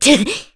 FreyB-Vox_Attack3_kr.wav